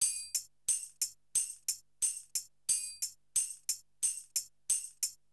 2021 Total Gabra Dholki Loops